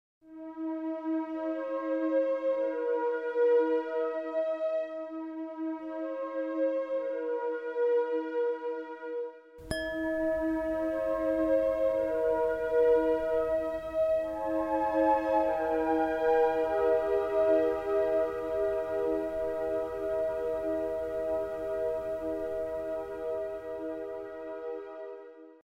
Sie hören jeweils eine begleitende auf die jeweilige Frequenz abgestimmte Tonfolge und die Klangröhre, die exakt in der entsprechenden Frequenz schwingt.
639 Hz (Harmonische Beziehungen) - FAmuli tuorumKomposition "Harmonie"
639 Hz I.mp3